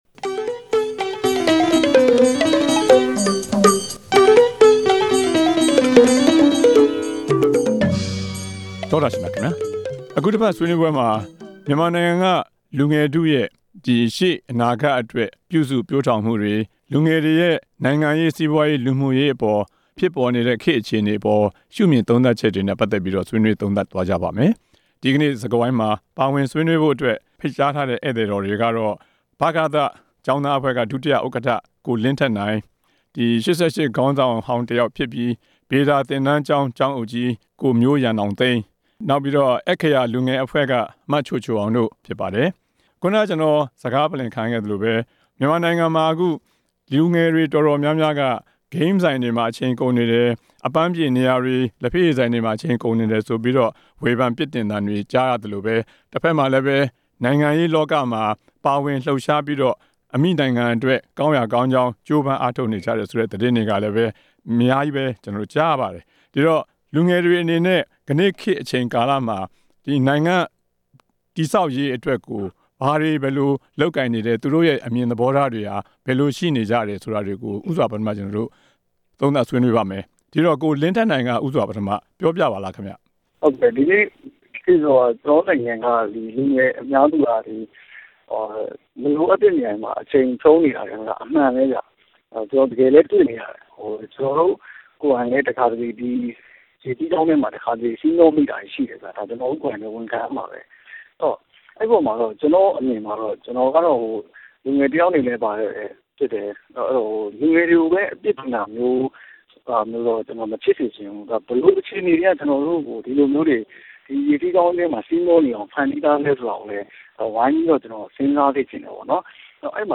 ဆွေးနွေးပွဲ စကားဝိုင်း
လူငယ်အဖွဲ့အစည်း ခေါင်းဆောင်သုံးဦးနဲ့အတူ